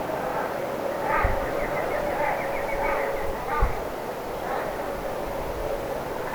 mikä tuo piipitys on,
onko sekin jokin ristisorsien ääni?
Kuulostaa vähän kuin lirolta,
Arvelen että joko sinisorsa tai ristisorsa.
mika_tuo_piipitys_on_onko_sekin_ristisorsan_aantelya.mp3